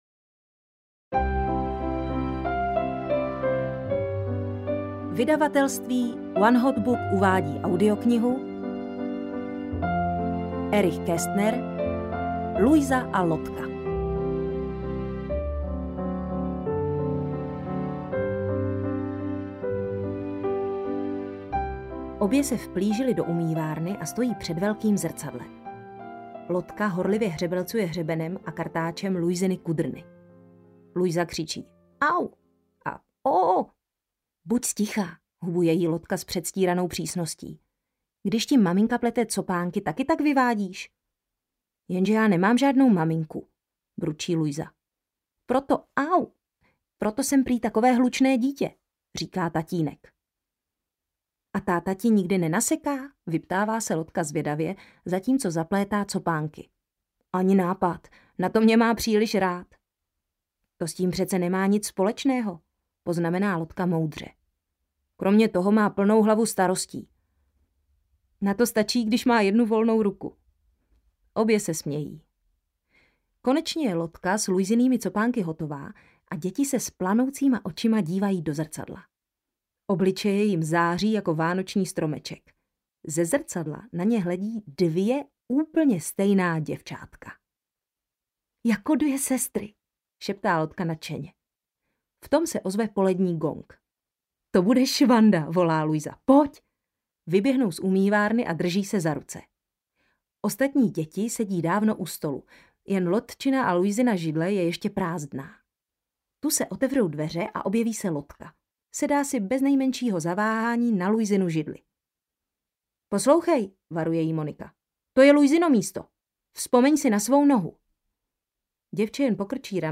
Luisa a Lotka audiokniha
Ukázka z knihy
• InterpretZuzana Kajnarová